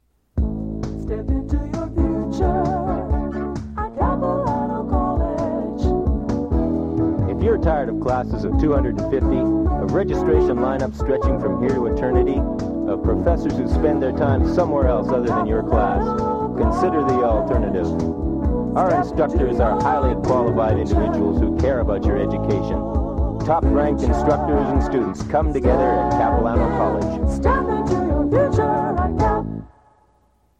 Audio non-musical
Capilano College Promotional Audio/Radio Jingle.
audio cassette